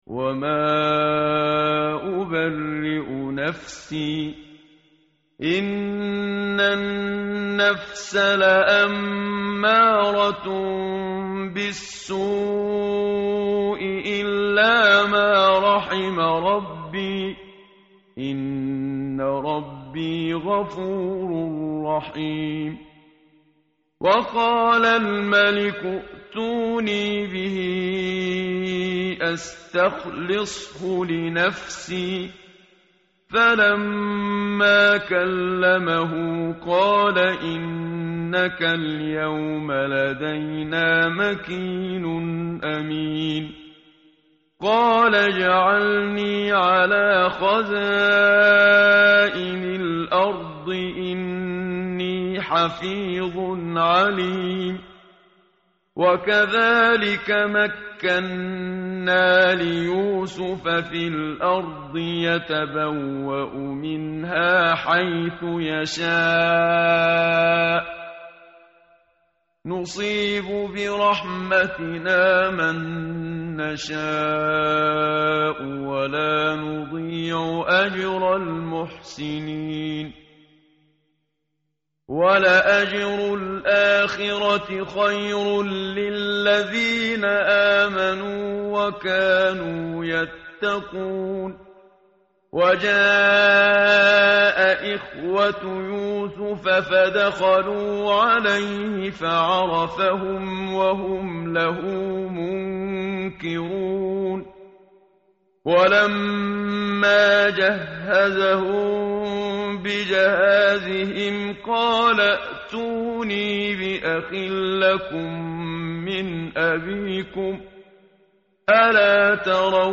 tartil_menshavi_page_242.mp3